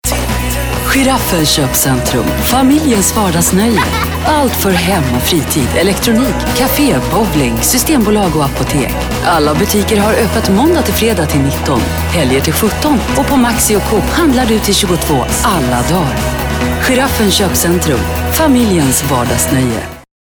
Commercial 2